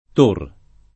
tor [ t 1 r ]